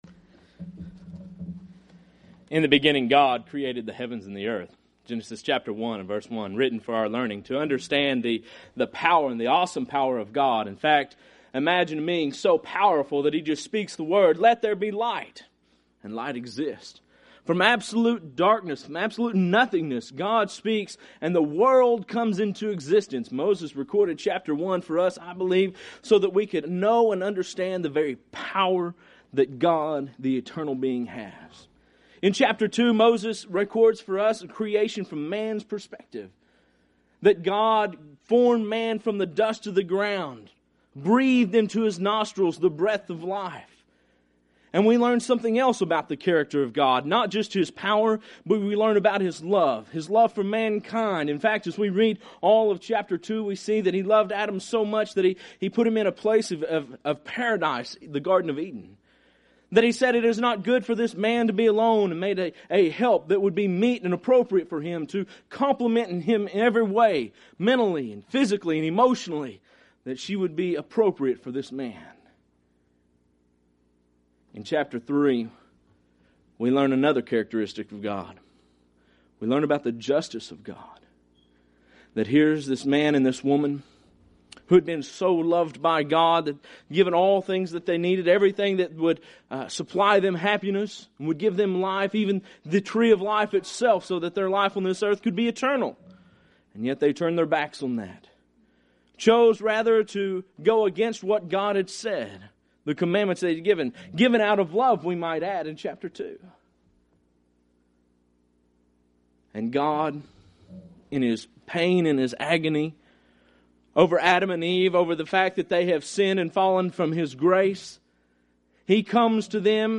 Event: 2003 Annual Shenandoah Lectures
lecture